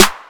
Yall Dont Really Hear Me Tho Snare.wav